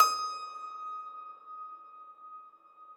53k-pno19-D4.wav